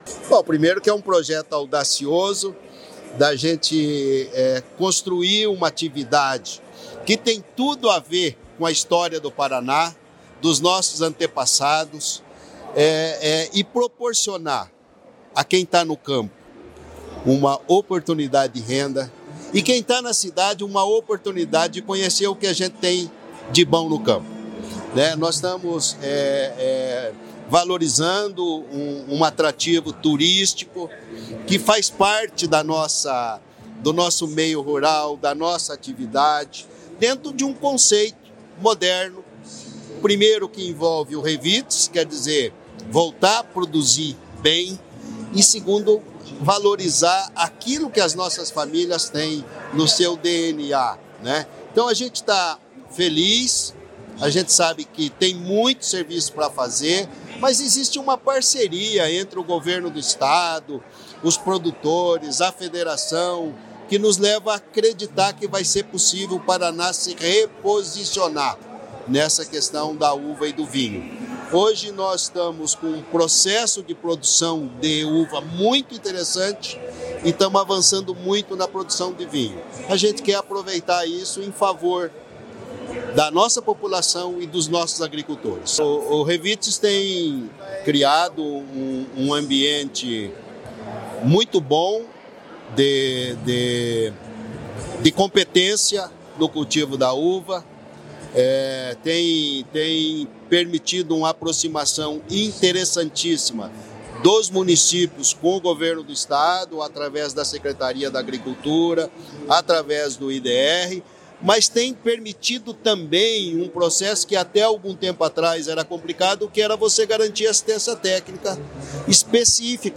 Sonora do diretor-presidente do IDR-PR, Natalino Avance de Souza, sobre a Rota da Uva & Vinho